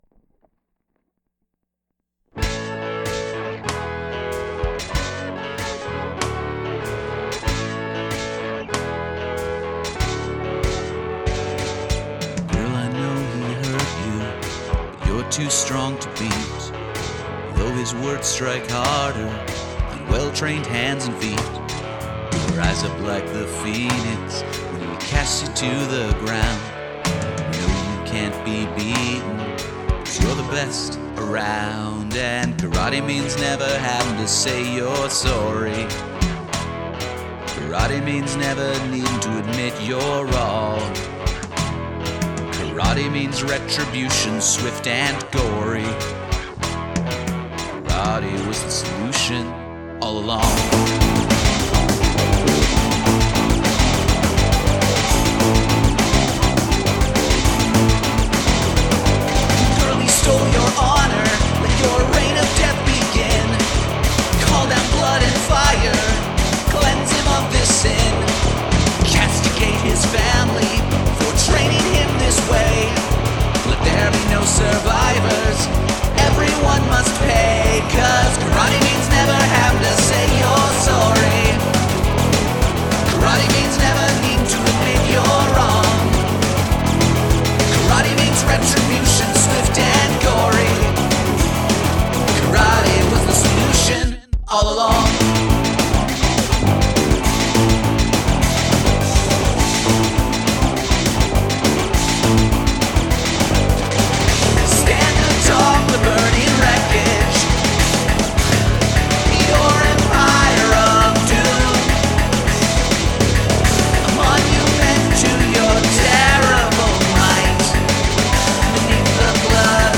Also: more bass.
Less snare.
That chorus hook is sweet.
There's not a lot of power in your vocal there, while the doubled guitars are playing loudly in the same register.
Guitars and bass sound cool.